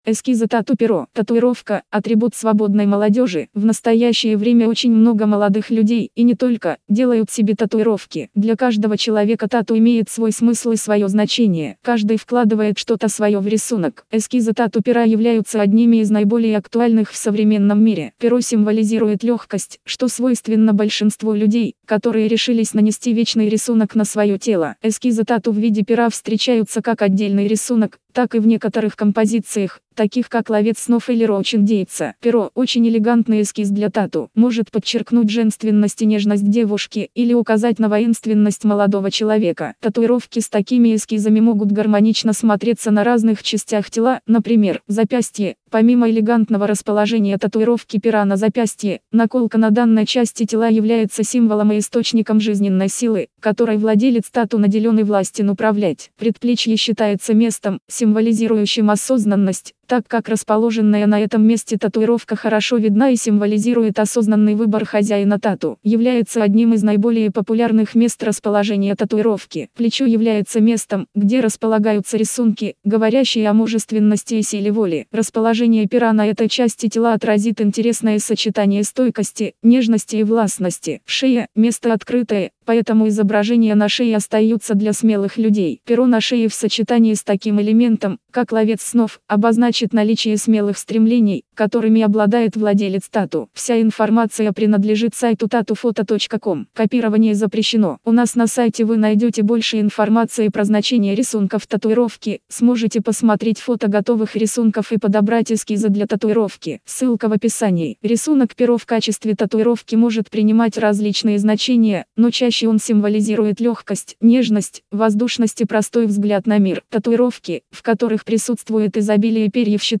Эскизы-тату-перо-аудио-версия-статьи-для-сайта-tatufoto.com_.mp3